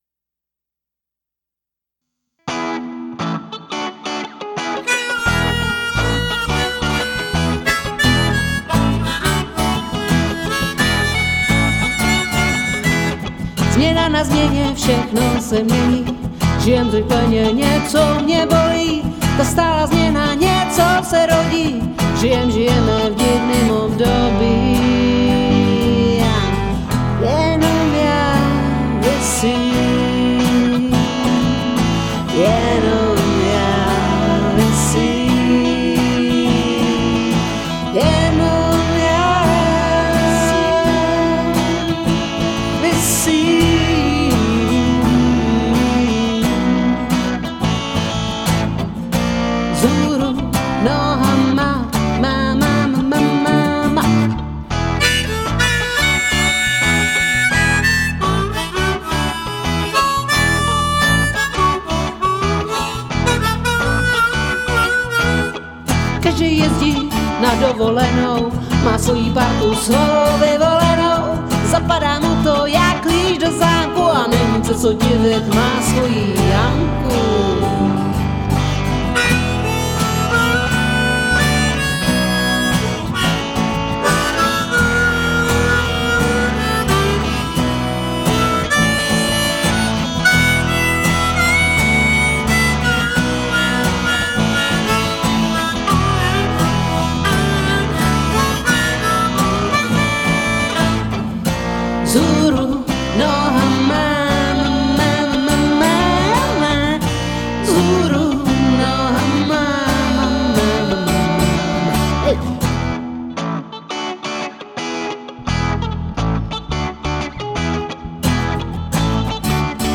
perkuse/bicí